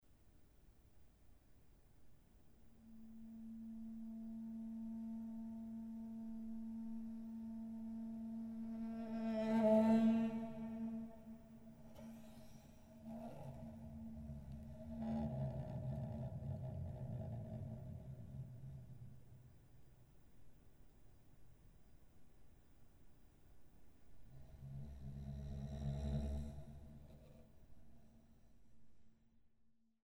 Violoncello und Arrangements